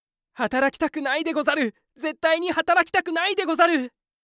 ミーム・ネタ系ボイス素材　2
SNS・投稿サイト・バラエティ番組等で見かけるあれやこれやのネタ・コラ画像等フレーズの声素材
働きたくないでござる_2025モノラル修正版.mp3